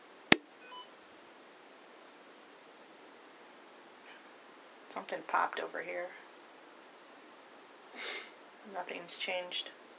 Equipment use: SLS Camera, digital recorders, Ovilus, EMF meters
Audio Captured during Paranormal Investigation
Whisper
Colon-Whisper-2.wav